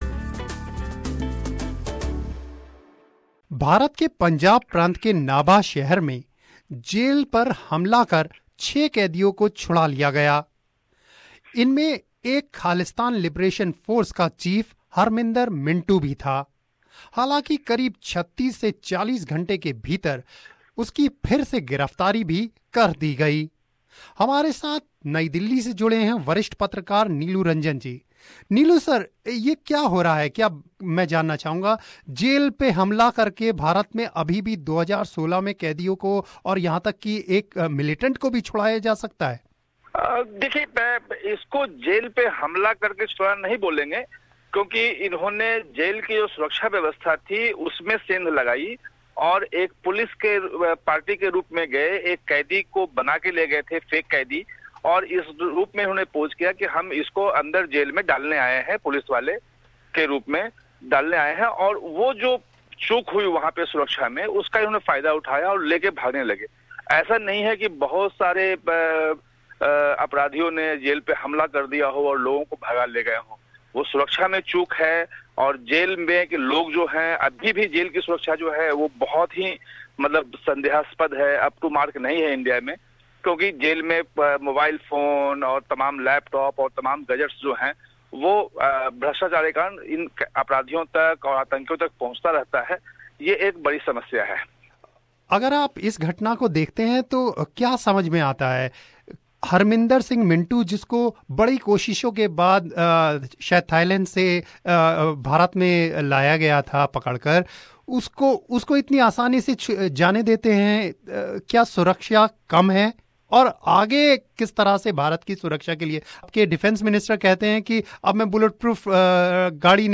नई दिल्ली स्थित वरिष्ठ पत्रकार